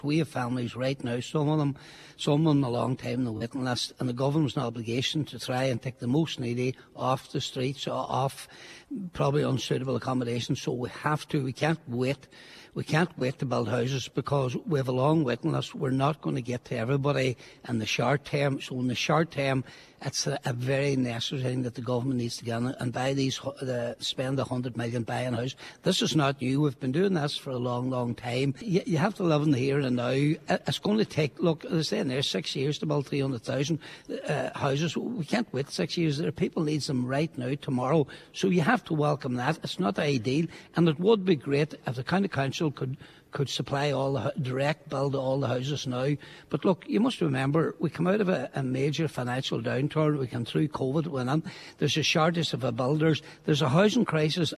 Cllr Patrick McGowan welcomed the action from government on today’s Nine til’ noon show, saying it is a necessary move: